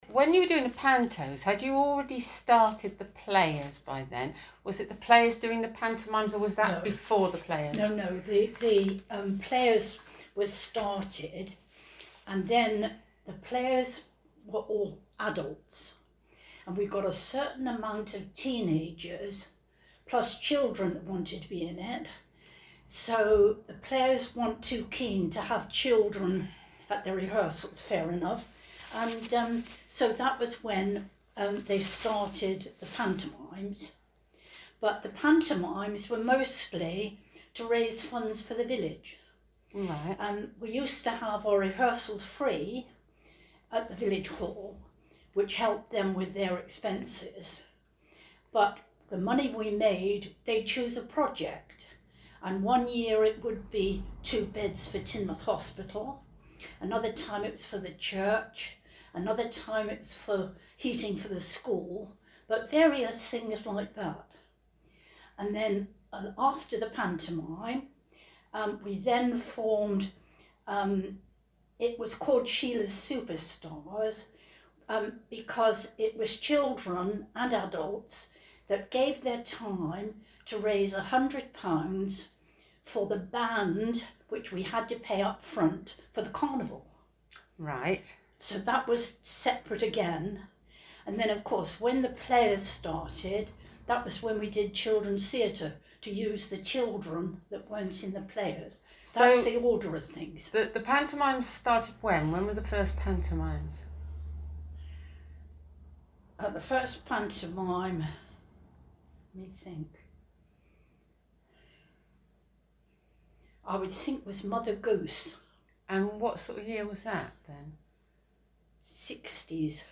A transcription and sound recording of an interview